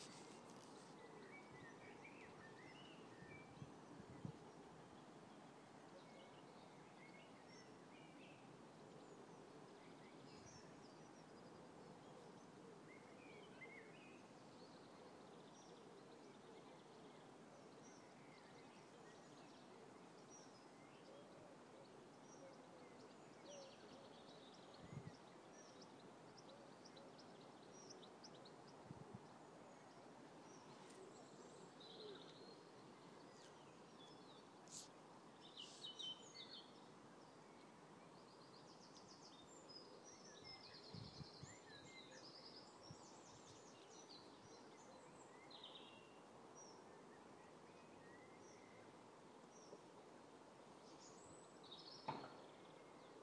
A minute of live tweeting by the River Foss